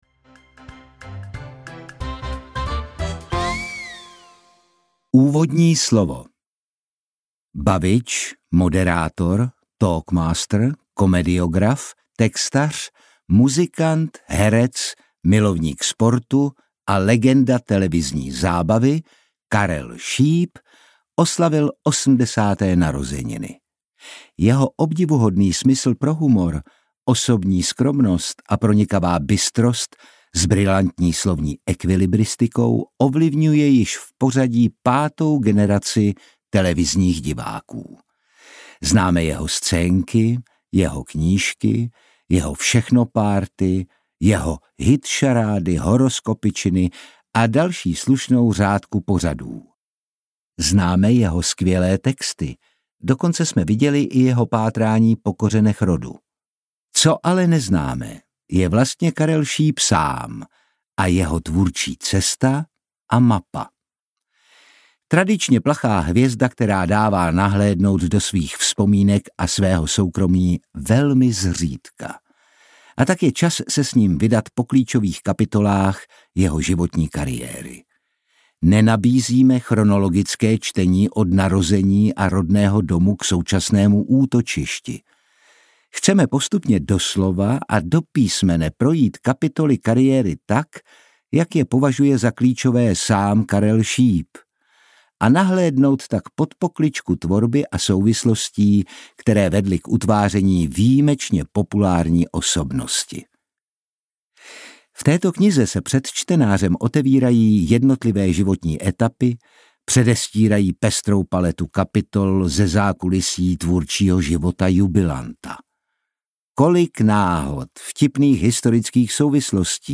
Čekání na smích audiokniha
Ukázka z knihy
• InterpretOtakar Brousek ml.